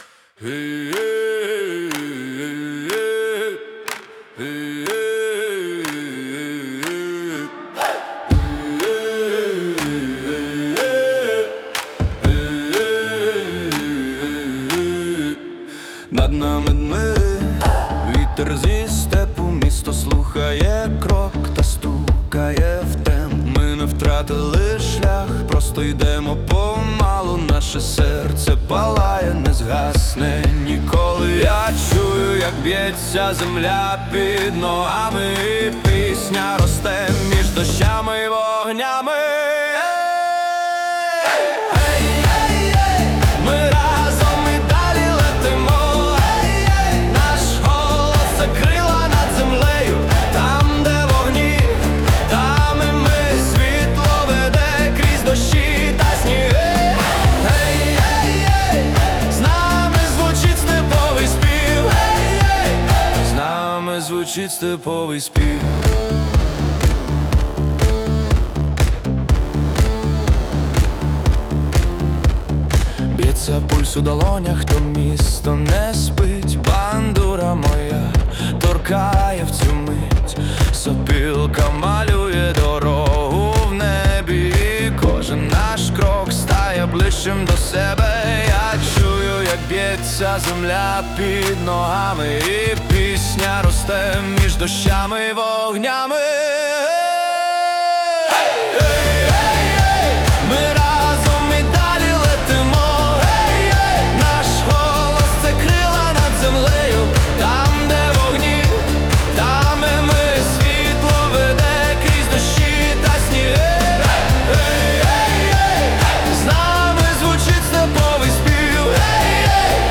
Стиль: Етно поп